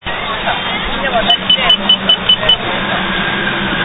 金山駅・アスナル金山付近の押しボタン式信号で青いボタンをタッチした時のピッピッピッピッピッピッピッのタッチ音です。